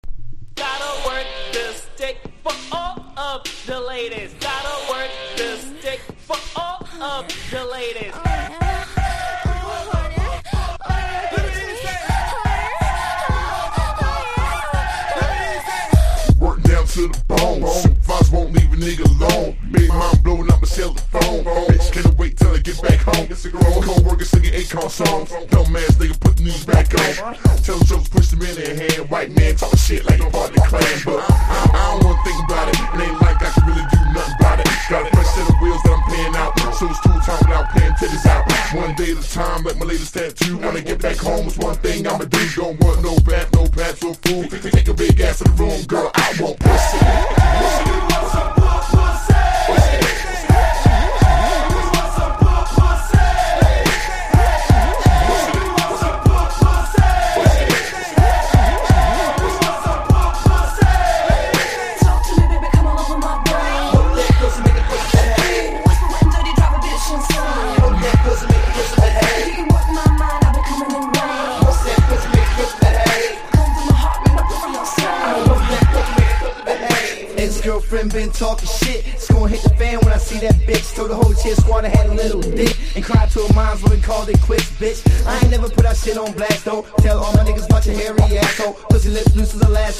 バイリファンキとオールドスクール・エレクトロの融合サウンド！マッチョなパワフル･ナンバー！